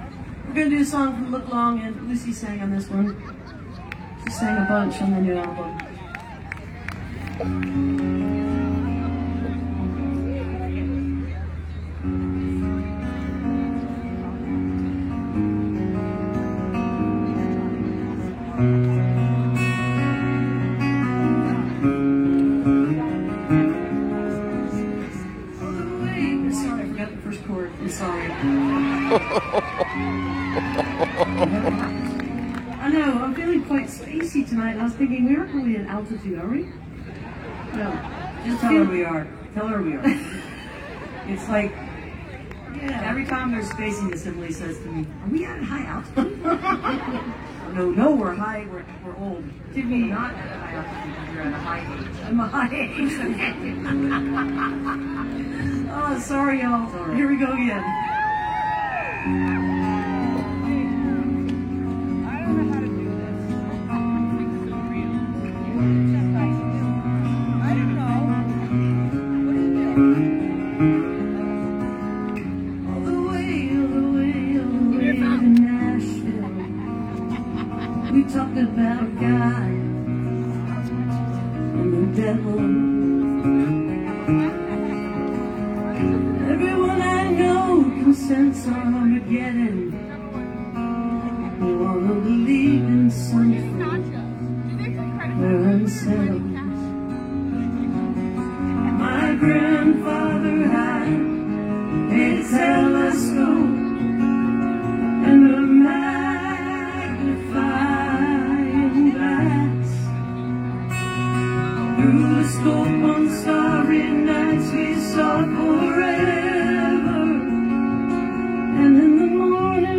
(captured from the facebook livestream of part of the show)